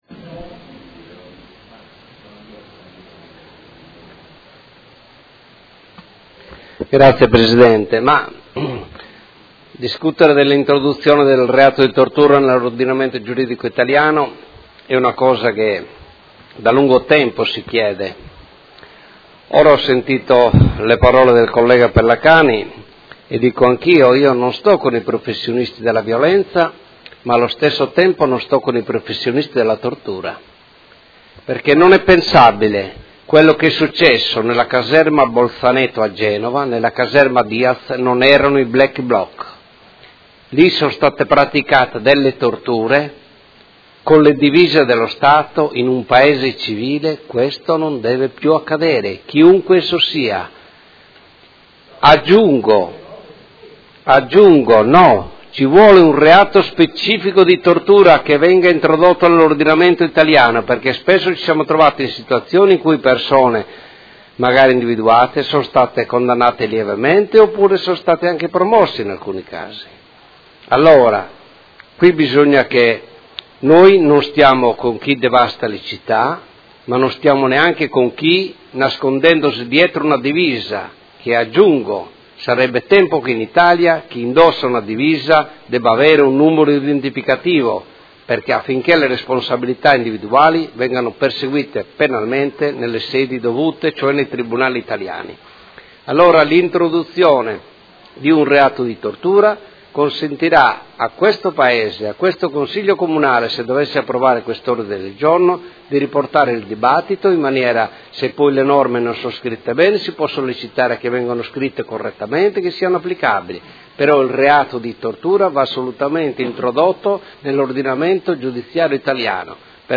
Seduta del 29/09/2016 Dibattito. Ordine del Giorno presentato dai Consiglieri Campana e Chincarini (Per Me Modena), Rocco (FASSI), Scardozzi (M5S), Cugusi (SEL) e Trande (PD) avente per oggetto: Introduzione nell’ordinamento del reato di tortura